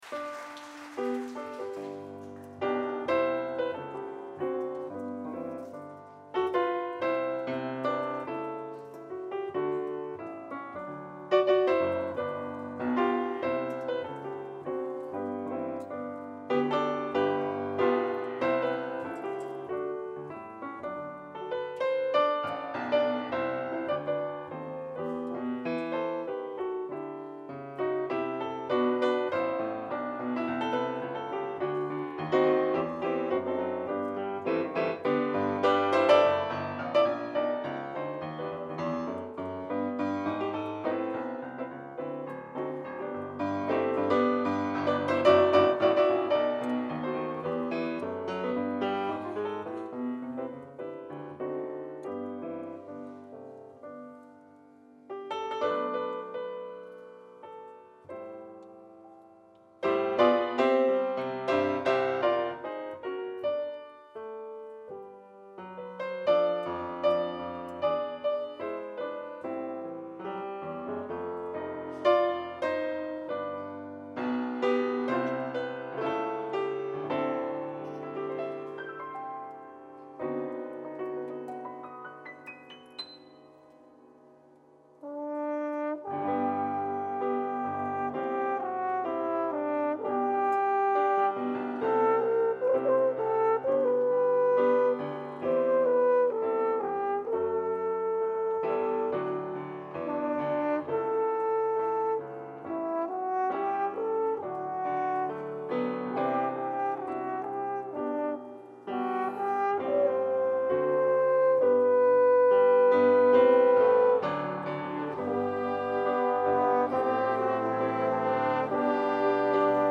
bateria
trompeta
piano
Contrabaix